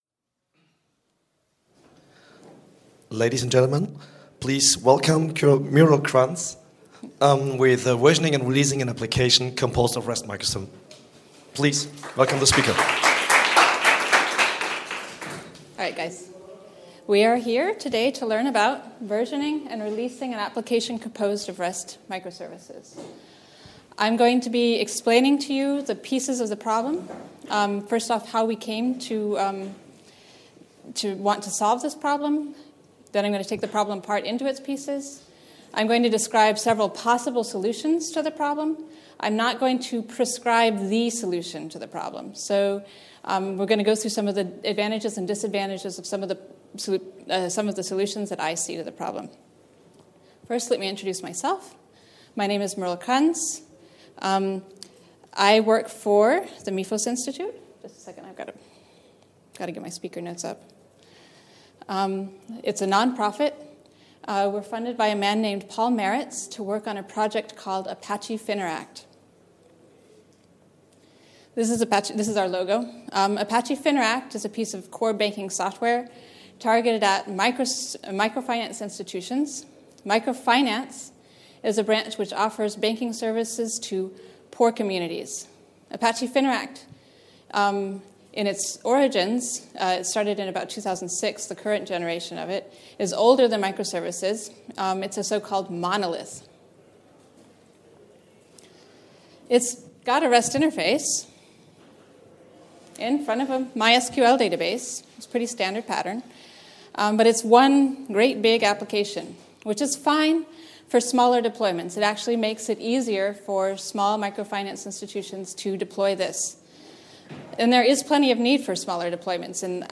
ApacheCon Seville 2016 – Versioning and Releasing an Application Composed of REST Microservices